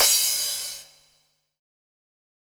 normal-hitfinish.wav